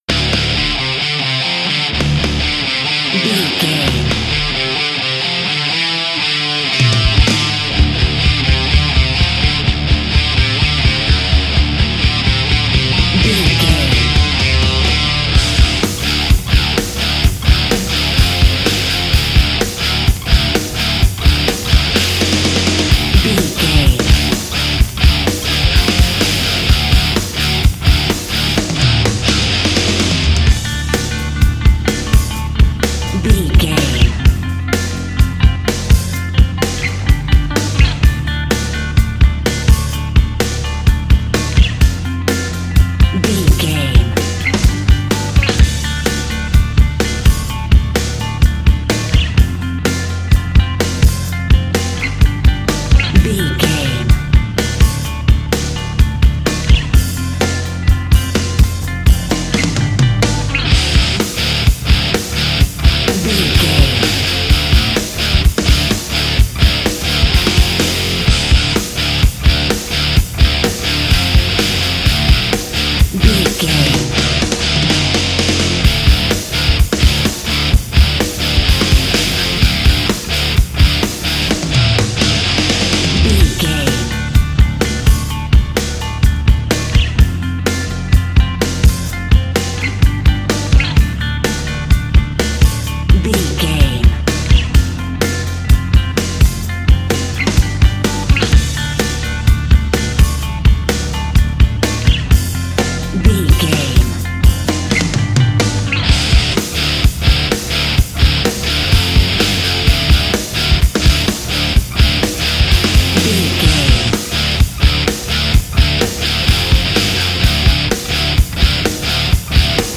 Epic / Action
Aeolian/Minor
drums
electric guitar
bass guitar
hard rock
aggressive
energetic
intense
nu metal
alternative metal